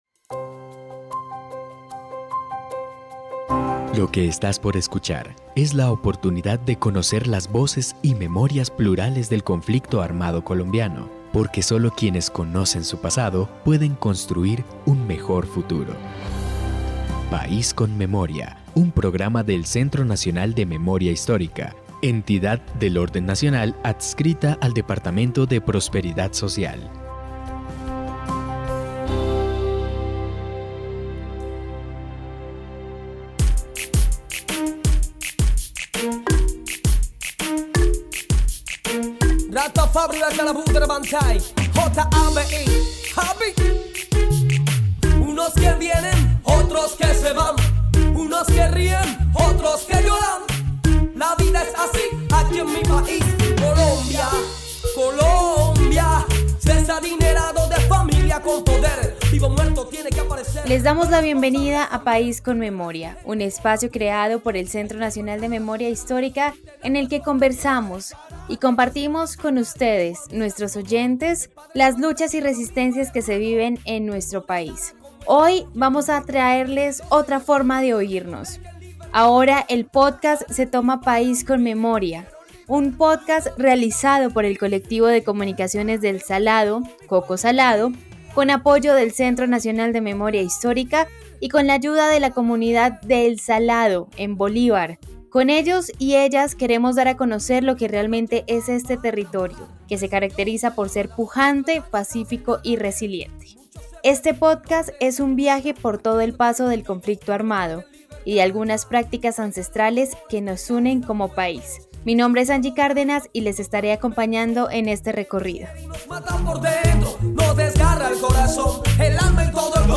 En País con Memoria hablamos con el colectivo de comunicaciones sobre este proceso.
Descripción (dcterms:description) Capítulo número 24 de la cuarta temporada de la serie radial "País con Memoria". Memorias de una comunidad que sueña con dignificar y visibilizar las voces de aquellos que el conflicto armado ha intentado silenciar.